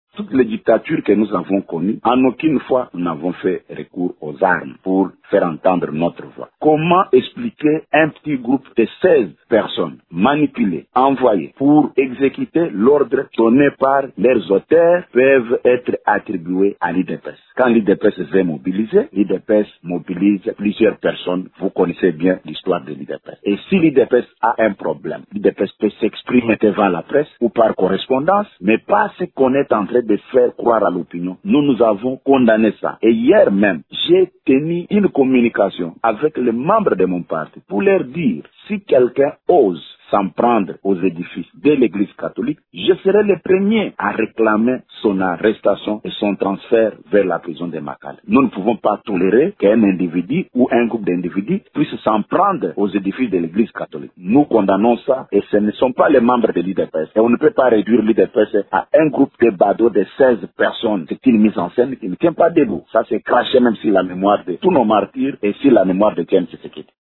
Lors d’une conférence de presse tenue lundi 2 août, elle estime que « toutes ces attaques manifestent une haine contre l’Eglise catholique. »